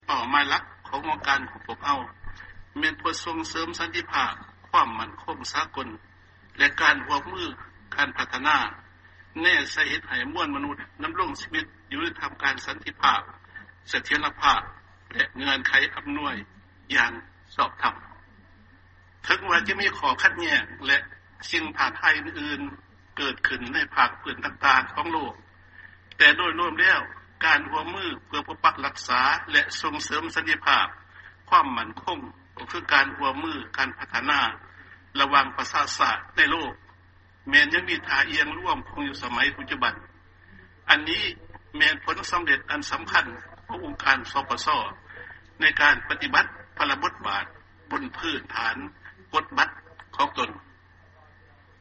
ຟັງຖແຫລງການ ປະທານປະເທດ ຈຸມມະລີ ໄຊຍະສອນ 2